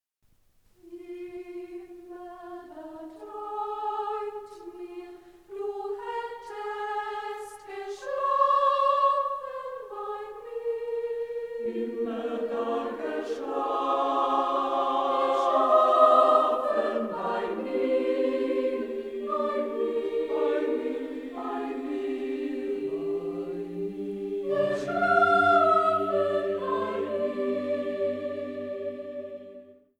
erklangen in Festgottesdiensten